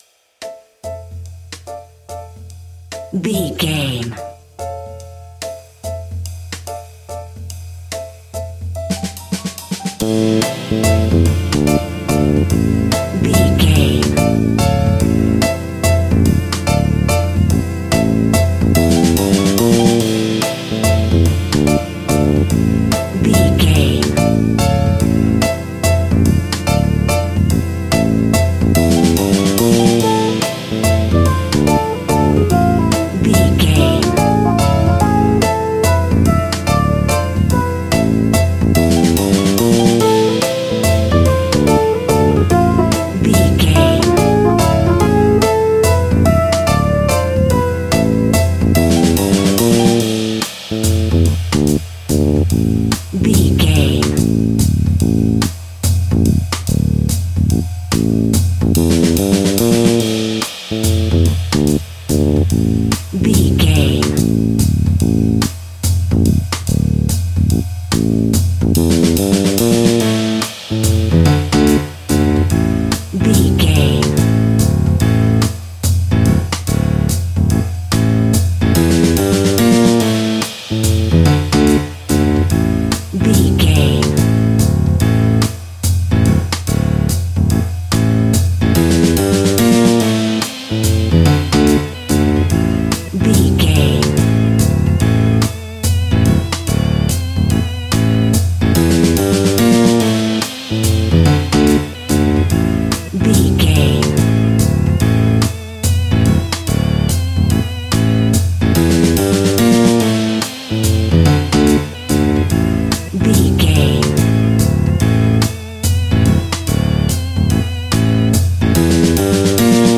Aeolian/Minor
scary
ominous
haunting
eerie
playful
electric organ
electric piano
drums
synthesiser
spooky
horror music